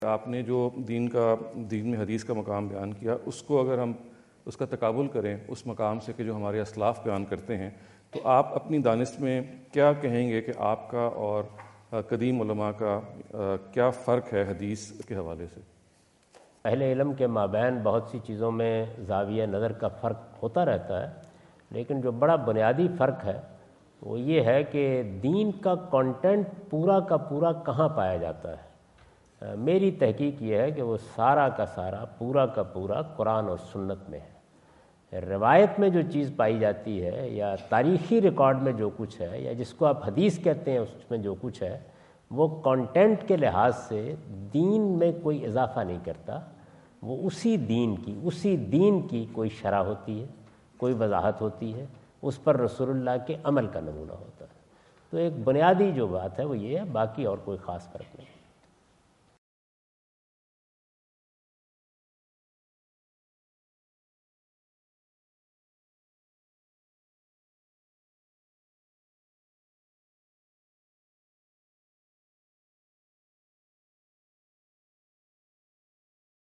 Javed Ahmad Ghamidi answer the question about "Mr. Ghamidi's Approach Towards Hadith" asked at The University of Houston, Houston Texas on November 05,2017.